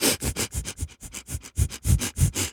pgs/Assets/Audio/Animal_Impersonations/wolf_breathing_sniff_01.wav at master
wolf_breathing_sniff_01.wav